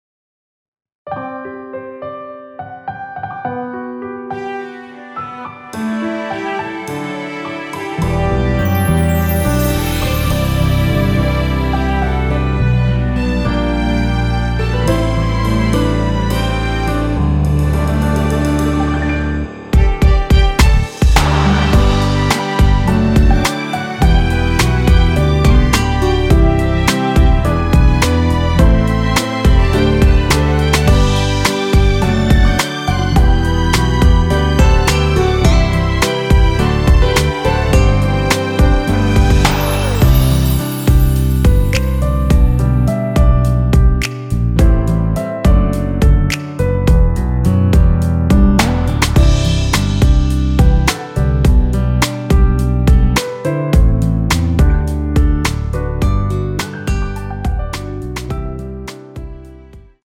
원키에서(-1)내린 MR입니다.(미리듣기 확인)
앞부분30초, 뒷부분30초씩 편집해서 올려 드리고 있습니다.